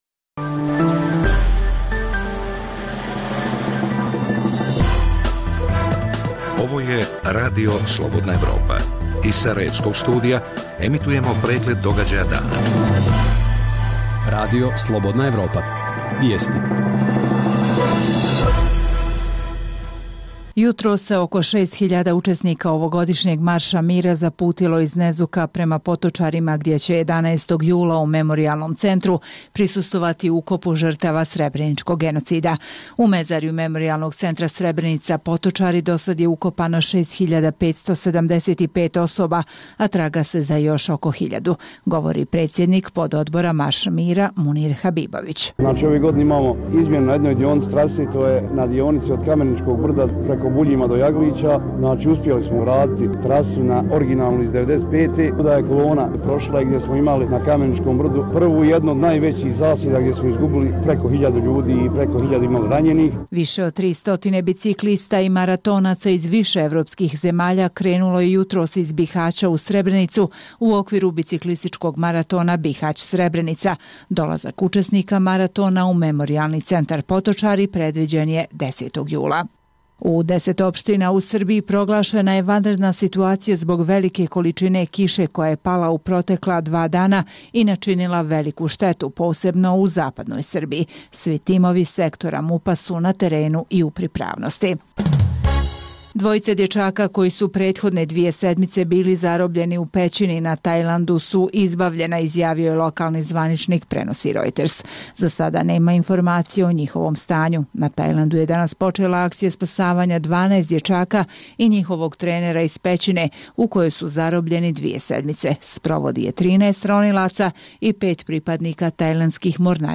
sadrži vijesti, analize, reportaže i druge sadržaje o procesu integracije BiH u Evropsku uniju i NATO.